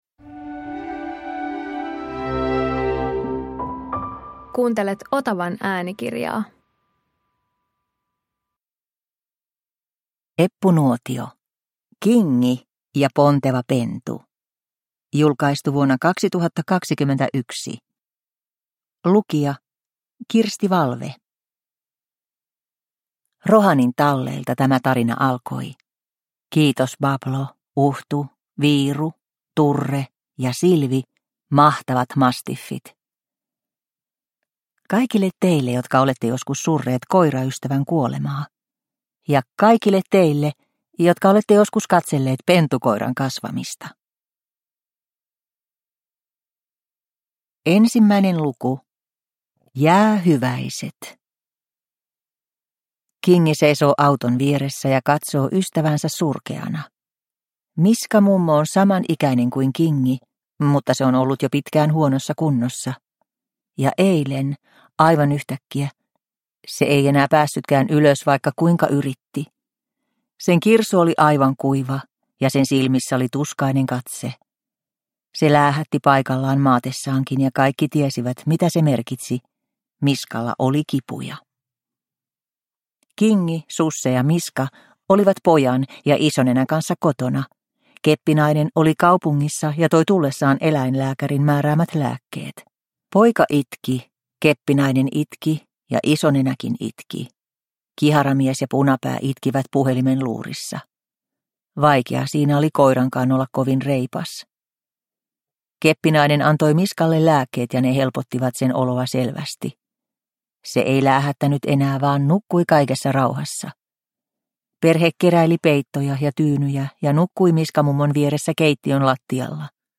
Kingi ja ponteva pentu – Ljudbok – Laddas ner